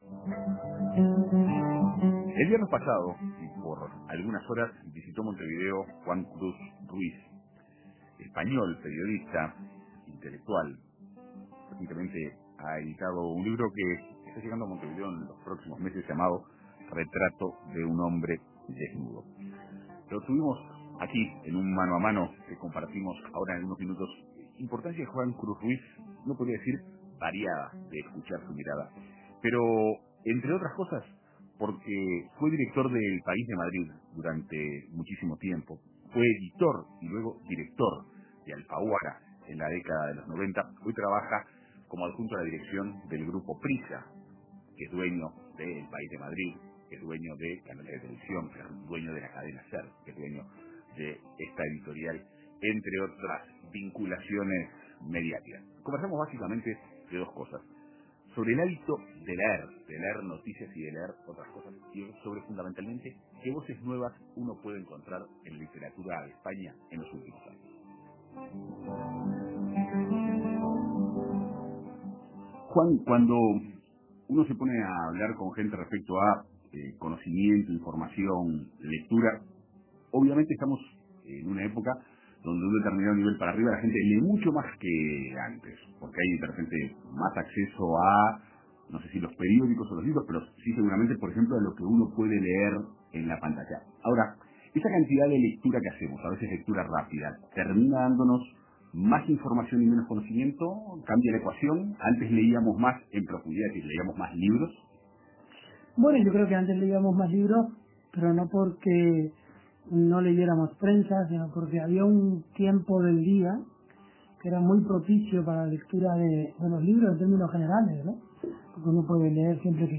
Entrevista con el escritor y periodista español Juan Cruz, ex director de El País de Madrid y de la editorial Alfaguara